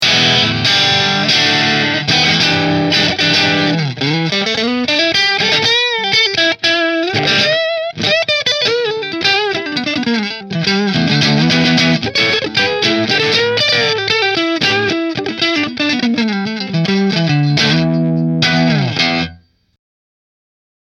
• 2 Custom Wound Humbuckers with LR Baggs Piezo System
Warrior Guitars The Dran Michael Natural Position 2 Through Marshall